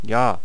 archer_ack7.wav